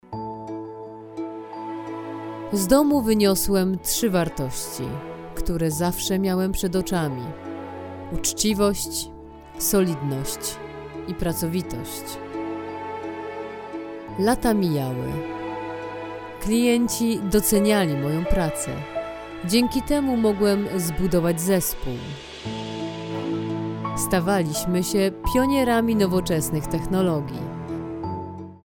Female 30-50 lat